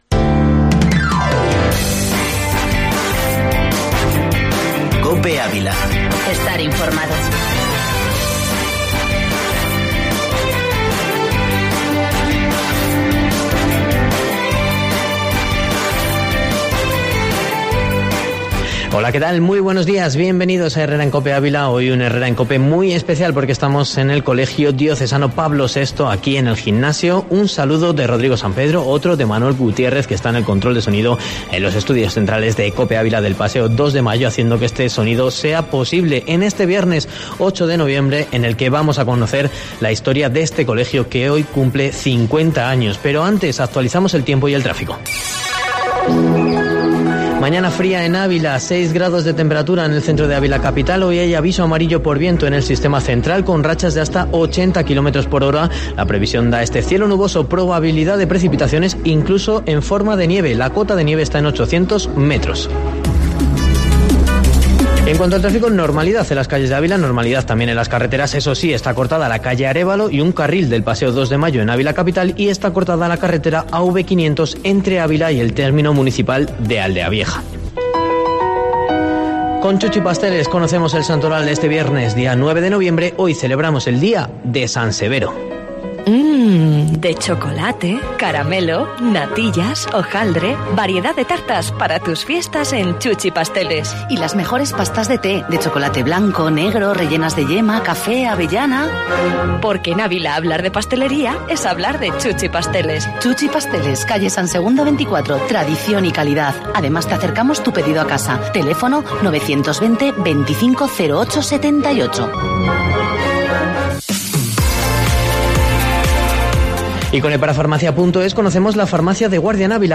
En la celebración ha participado COPE Ávila sacando los estudios hasta el propio colegio y emitiendo los espacios locales de Herrera en COPE, Mediodía y El Espejo.